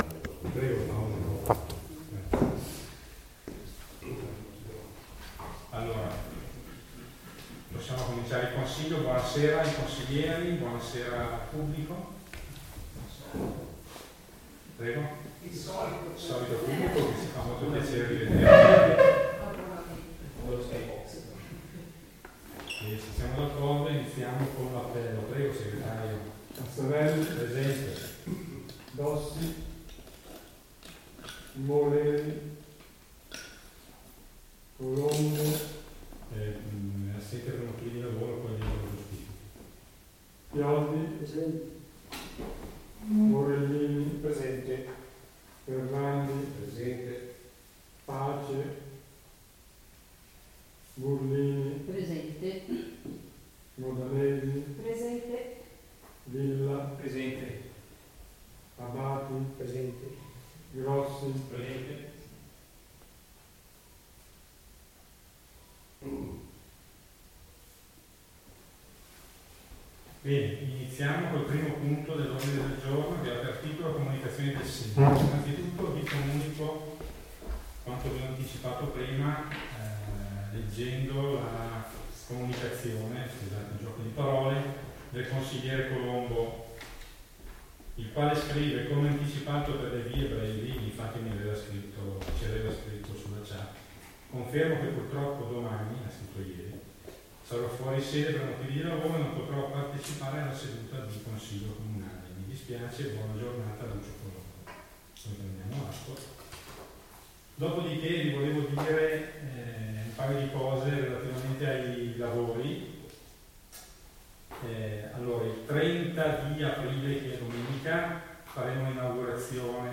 Registrazione seduta consiliare del 18.04.2023 | Comune di Fara Gera d'Adda